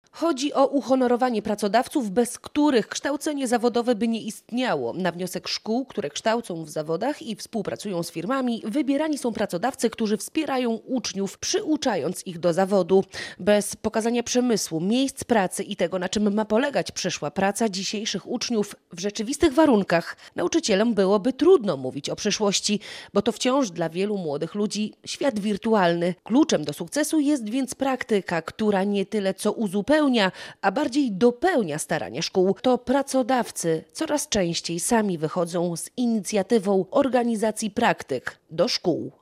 W Olsztyńskim Parku Naukowo-Technologicznym odbyła się szósta gala „Pracodawca Przyjazny Szkole”.